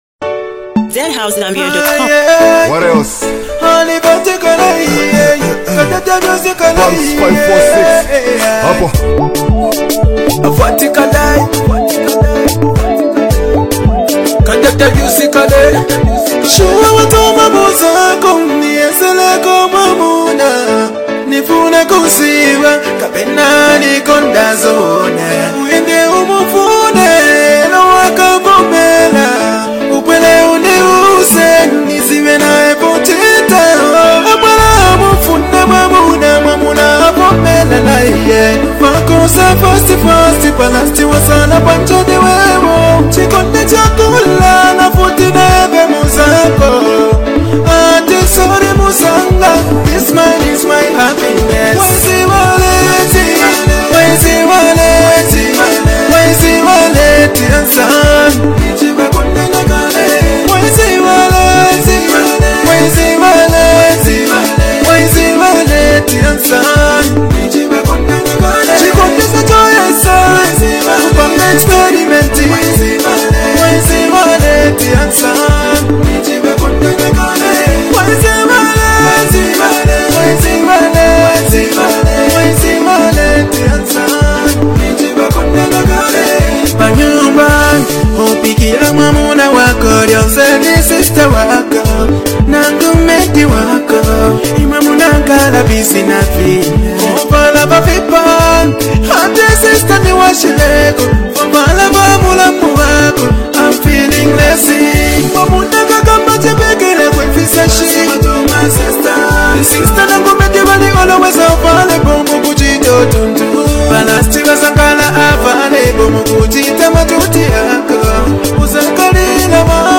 and street vibes.